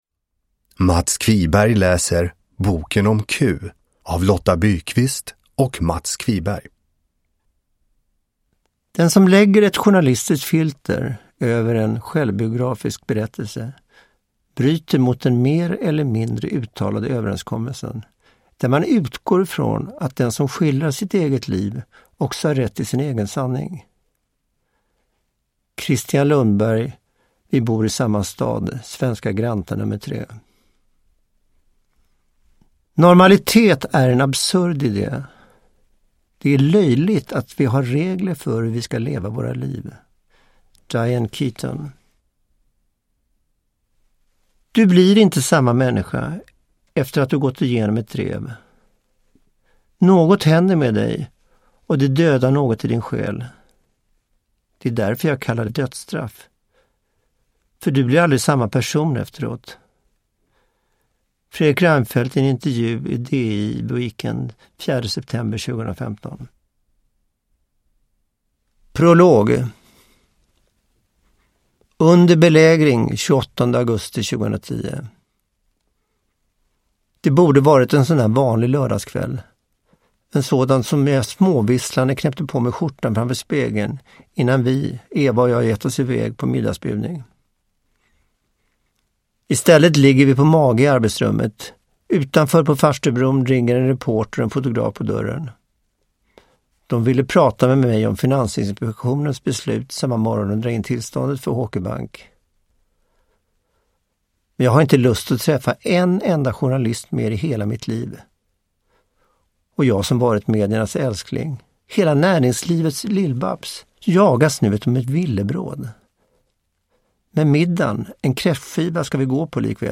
Uppläsare: Mats Qviberg
Ljudbok